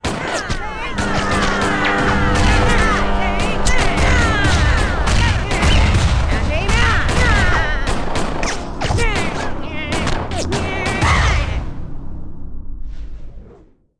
Theater Escape Sound Effect
theater-escape.mp3